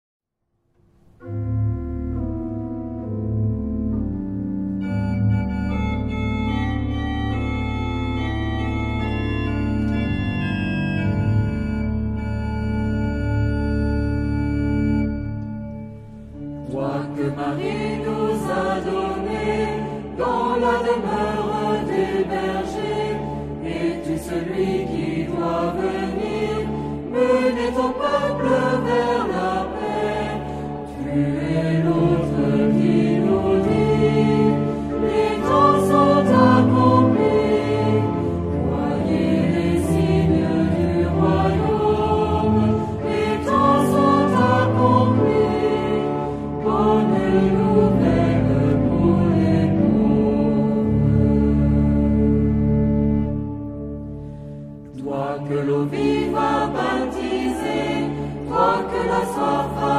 Genre-Style-Forme : Hymne (sacré) ; Sacré
Caractère de la pièce : rythmé
Type de choeur : SATB  (4 voix mixtes )
Tonalité : mode de ré